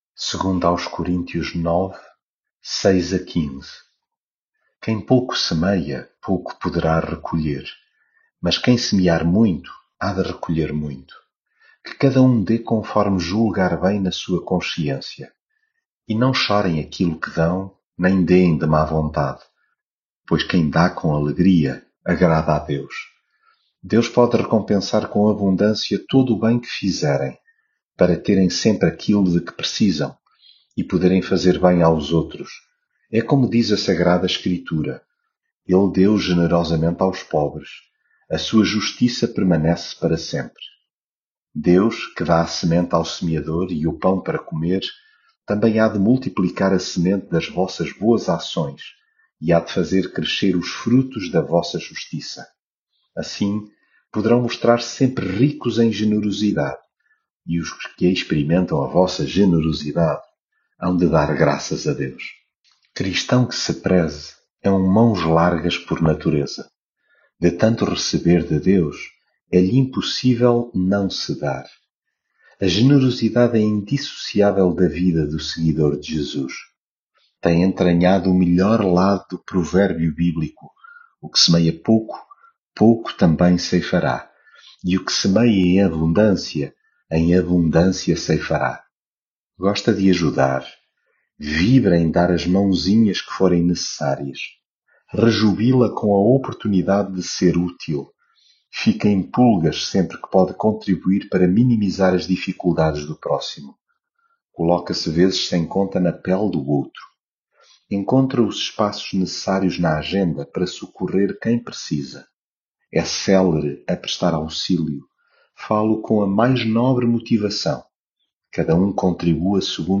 devocional coríntios
leitura bíblica